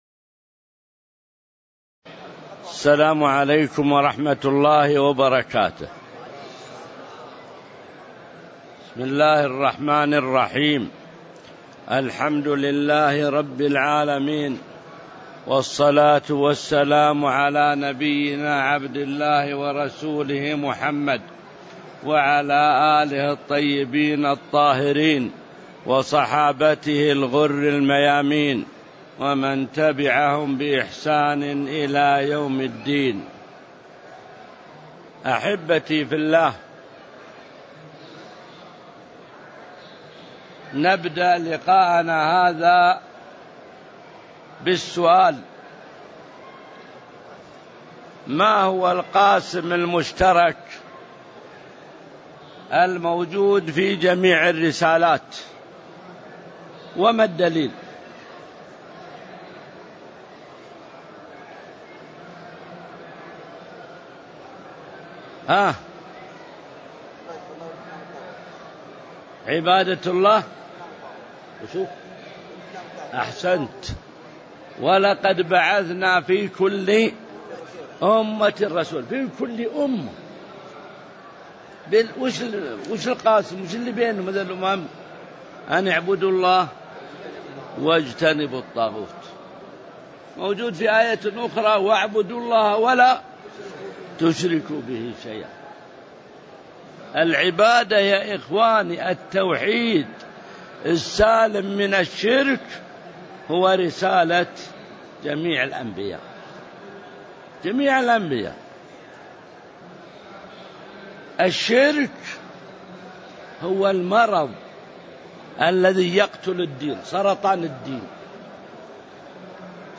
تاريخ النشر ٢٣ صفر ١٤٤٦ هـ المكان: المسجد النبوي الشيخ: معالي الشيخ د. عبدالله بن محمد المطلق معالي الشيخ د. عبدالله بن محمد المطلق أهمية التوحيد وفضله (01)